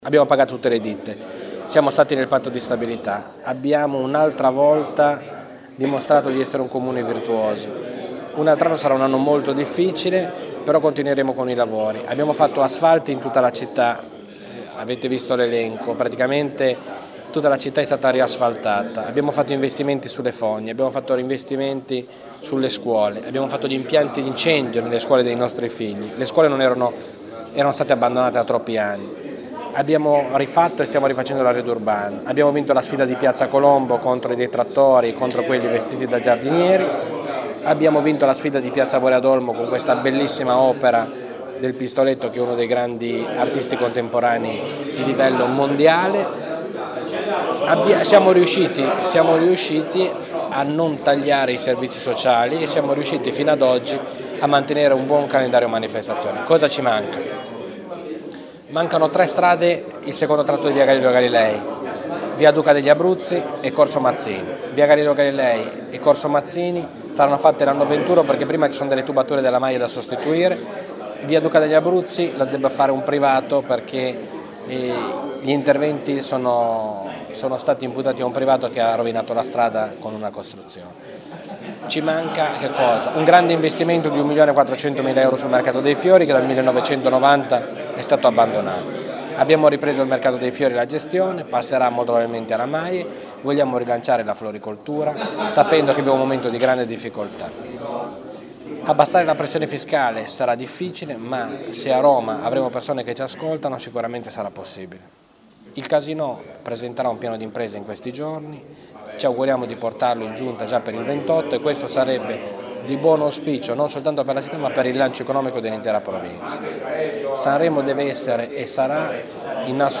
Il Sindaco di Sanremo, Maurizio Zoccarato, ha fatto il punto della situazione al termine del 2012, invitando nel suo ufficio i media, la Giunta, i Consiglieri Comunali ma anche semplici sostenitori ed amici, approfittando della vigilia di Natale, per fare a tutti anche gli auguri con un bicchiere di spumante rigorosamente italiano.